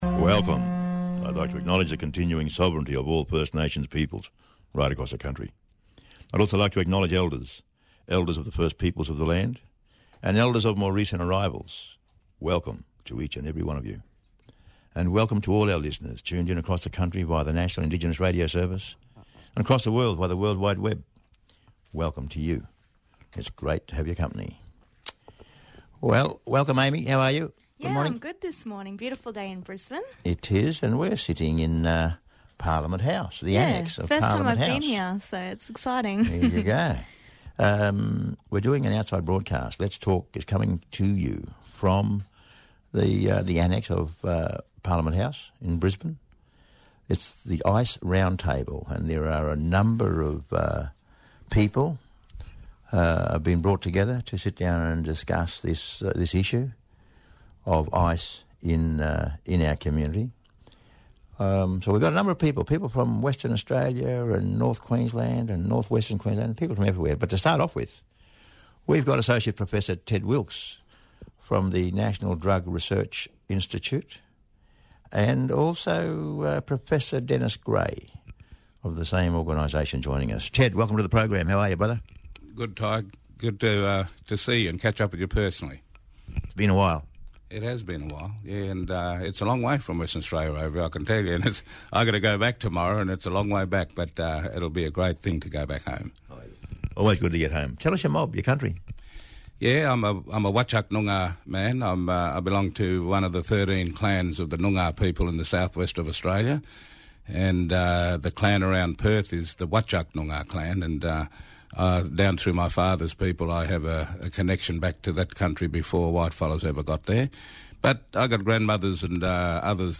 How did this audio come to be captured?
broadcast from Qld Parliament House where QAIHC is hosting a summit to find ways of reducing methamphetamine use in Aboriginal and Torres Strait Islander communities.